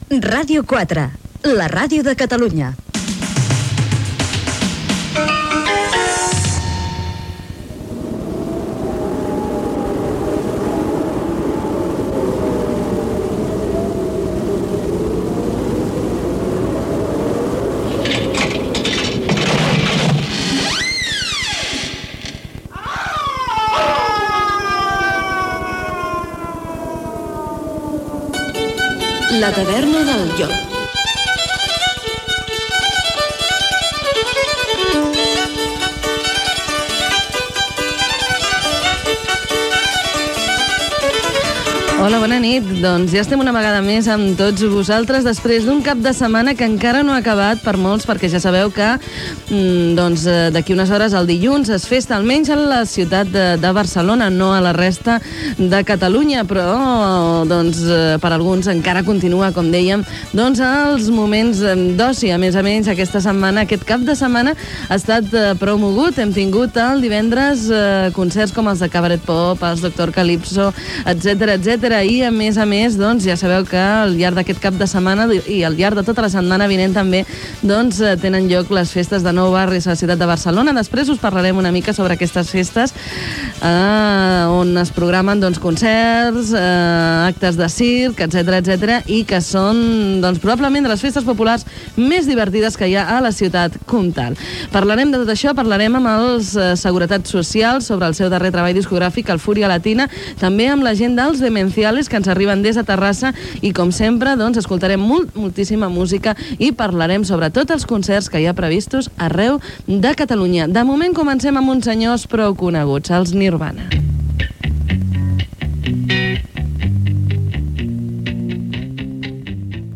Indicatiu de l'emissora, careta i presentació del programa, concerts del cap de setmana, festa major de Nou Barris i presentació d'un tema musical.
Musical